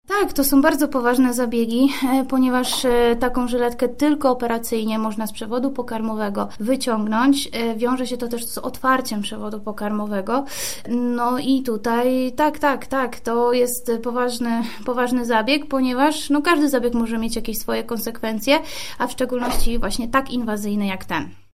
Wyciągnięcie takiego przedmiotu z żołądka to poważny zabieg – mówi lekarz weterynarii